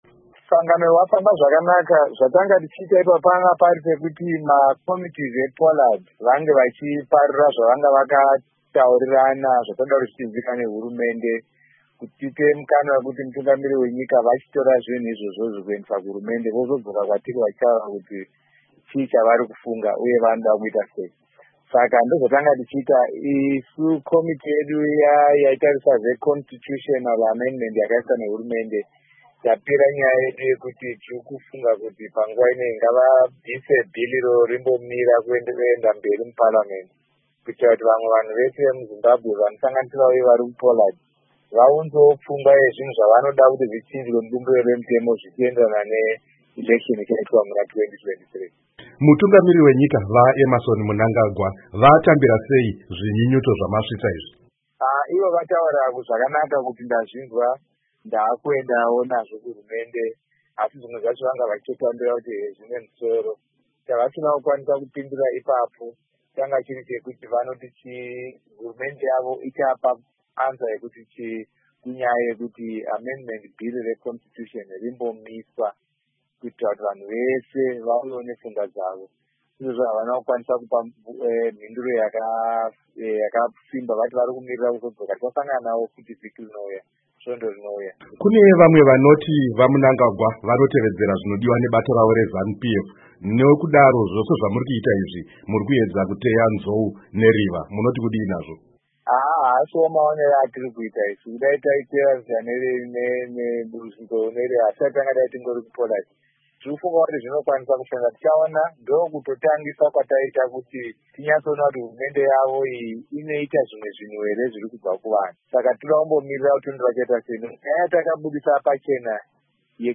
Hurukuro naVaLovemore Madhuku